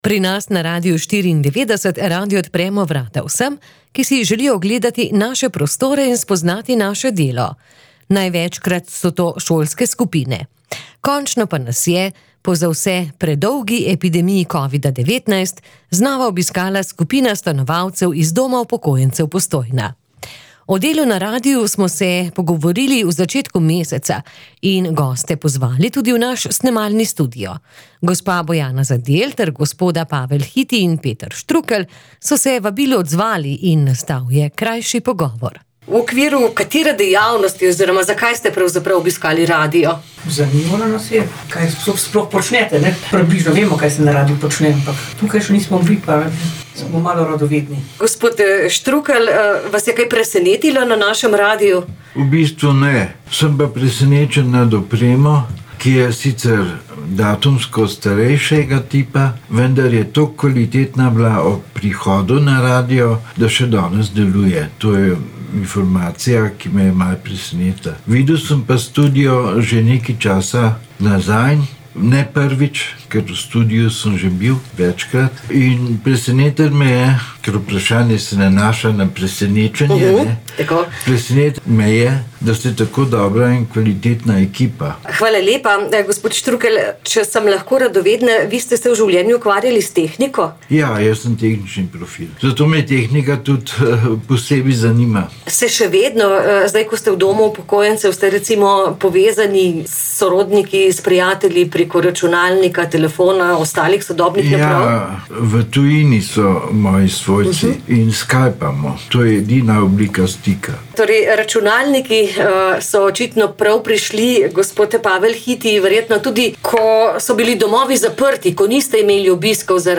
O delu na radiu smo se pogovorili v začetku meseca in goste pozvali tudi v naš snemalni studio.